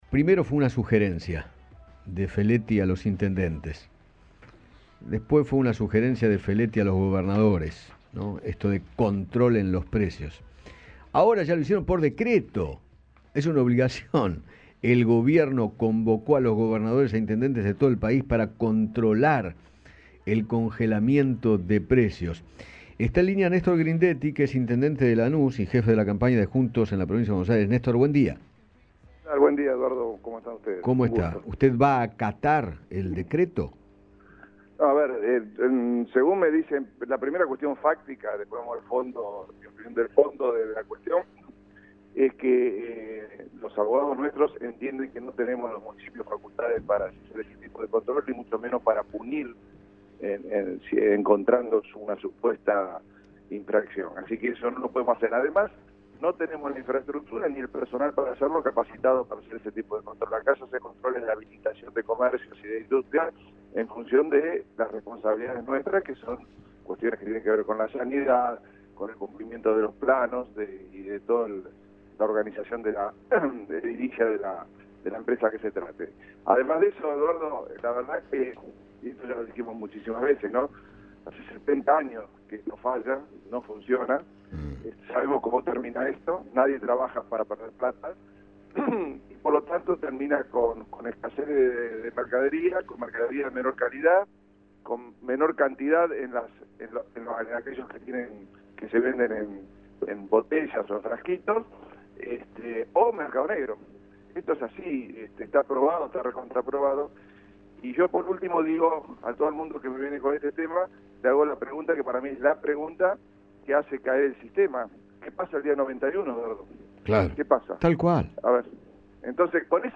Néstor Grindetti, intendente de Lanús, dialogó con Eduardo Feinmann sobre la convocatoria del Gobierno a gobernadores e intendentes para controlar los precios y sostuvo que “hace setenta años que esto no funciona, sabemos como termina”.